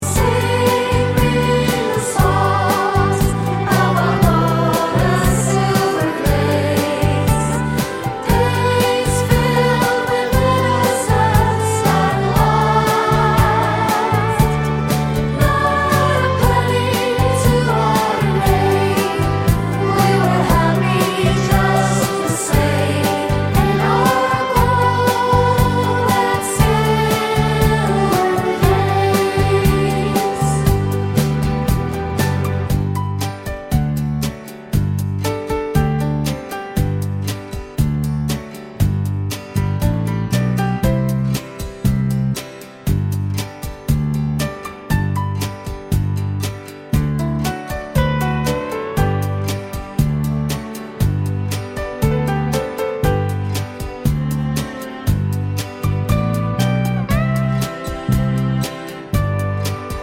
no Backing Vocals Irish 4:03 Buy £1.50